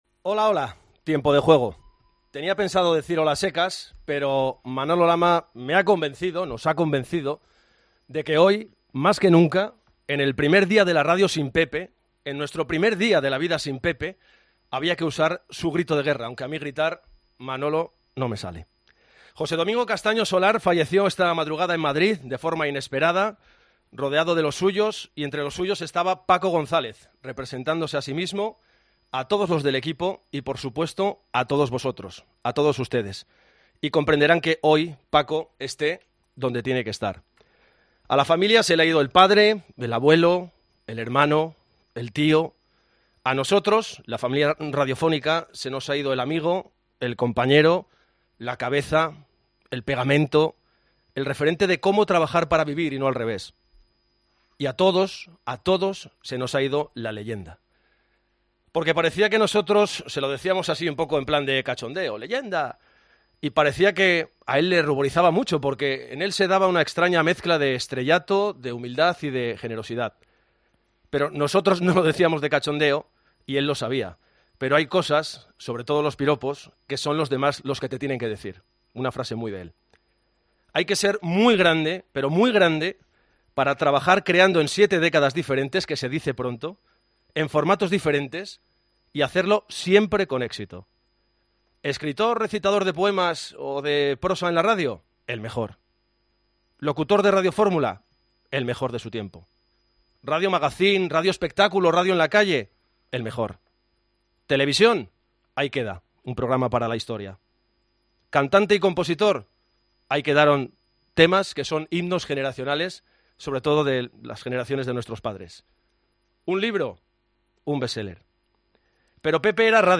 Sintonia del programa.
Paraules de Manolo Lama sobre Castaño.
Esportiu
FM